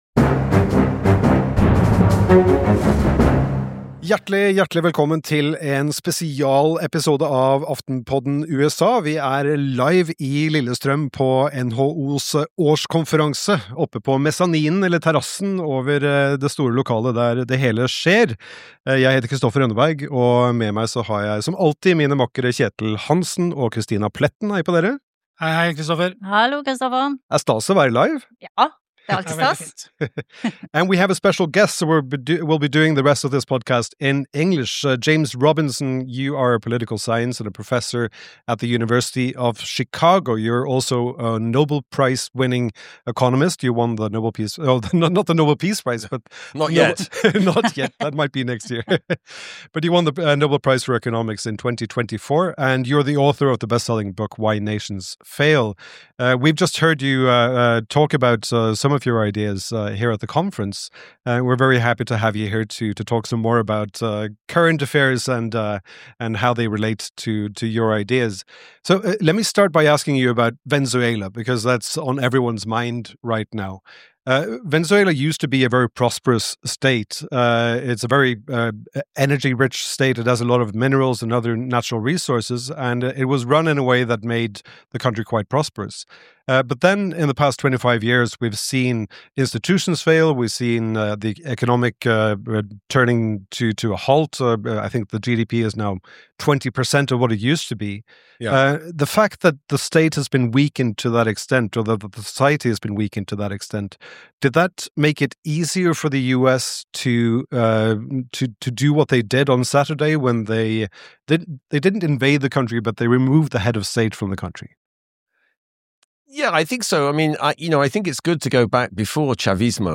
Hva skjer med USA når Trump river ned institusjonene som har bidratt til å bygge landet? I denne live-episoden fra NHOs årskonferanse snakker vi med James Robinson.